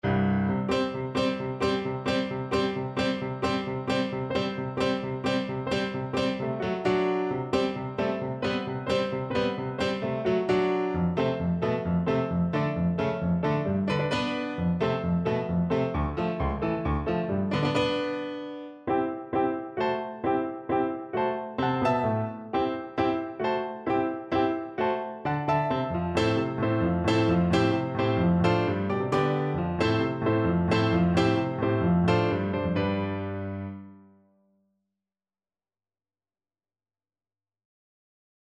C major (Sounding Pitch) (View more C major Music for Tuba )
Allegro =132 (View more music marked Allegro)
2/4 (View more 2/4 Music)
F2-G4
Classical (View more Classical Tuba Music)